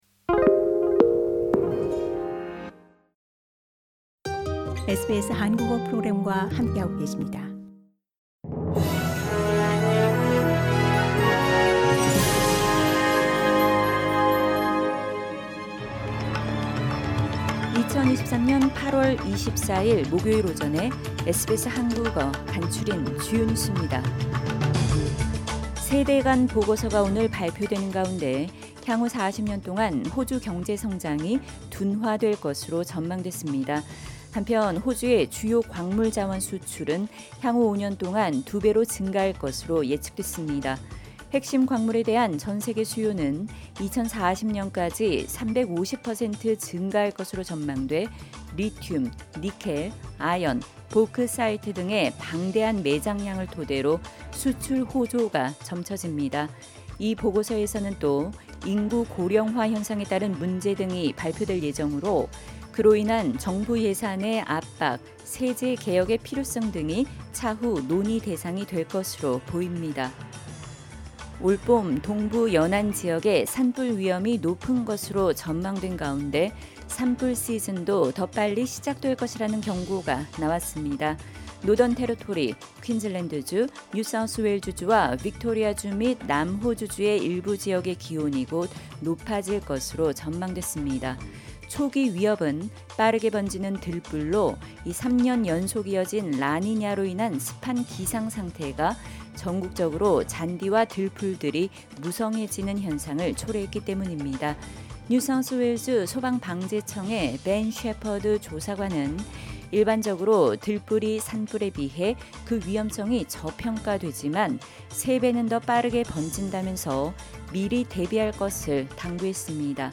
2023년 8월24일 목요일 아침 SBS 한국어 간추린 주요 뉴스입니다.